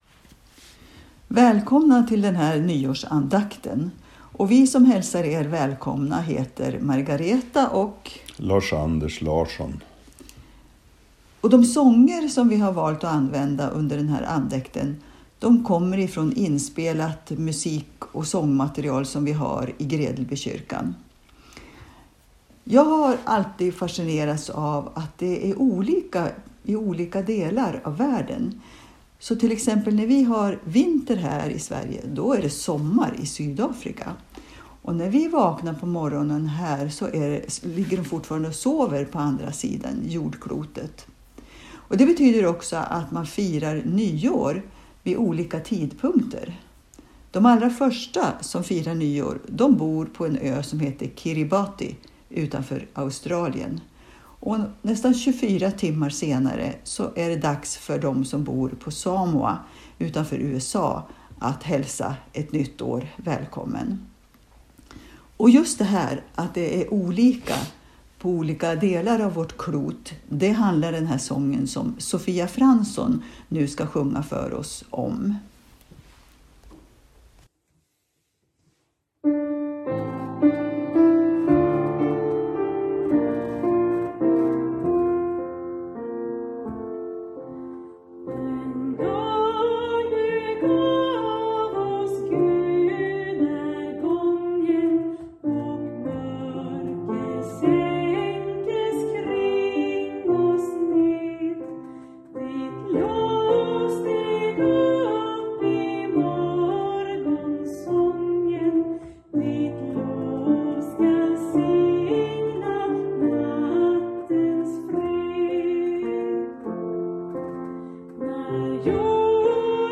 Inspelade gudstjänster i Gredelbykyrkan
Andaktsledare
Piano